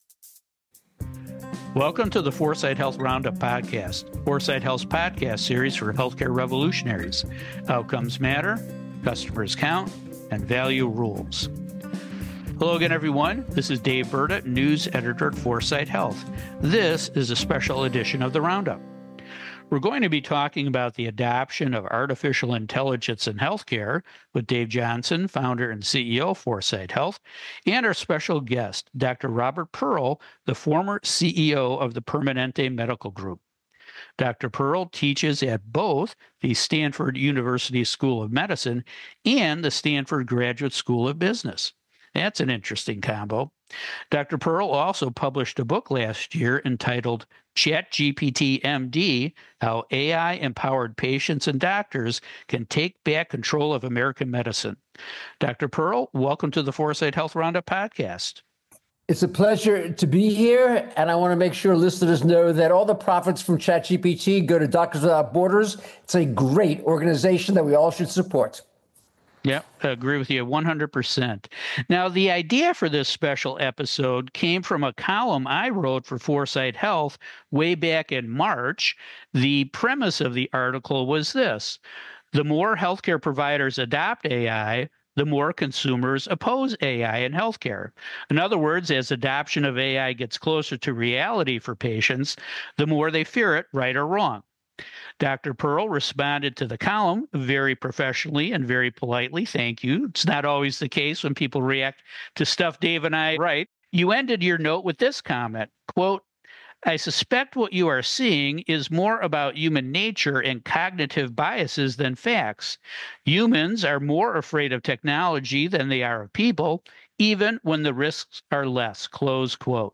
debate the acceptance and adoption of artificial intelligence tools in healthcare by providers and patients on this special episode of the 4sight Health Roundup podcast